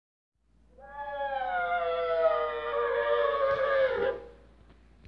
描述：一个先升后降的大鼓声音效果，通常用于帮助恐怖电影场景中的气氛设置。由一个旧的雅马哈PSRGX76键盘产生，通过Tascam US122 MKII音频/MIDI接口录制到Cubase LE5音乐制作软件。
标签： 鼓阶 爬行 恐怖 可怕
声道立体声